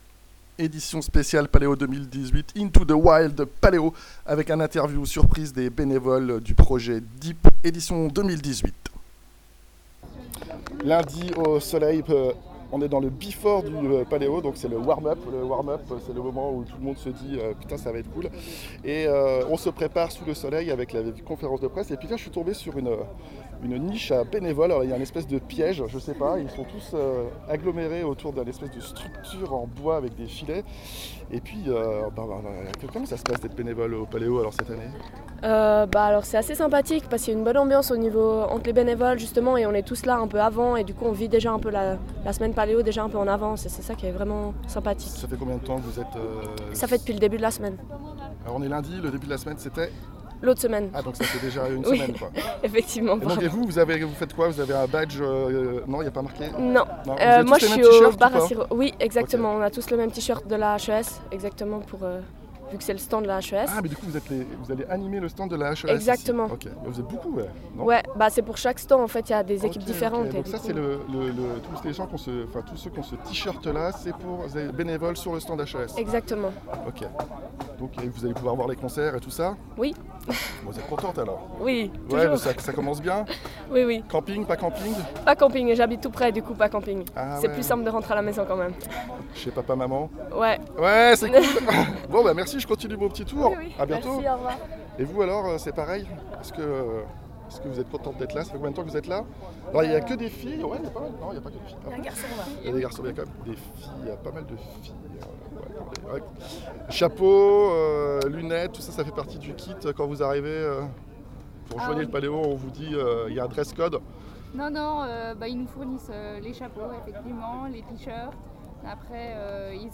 Paleo 2018: Interview des benevoles du projet DEEP, création originale et éphémère réalisée par la HES-SO Haute école spécialisée de Suisse occidentale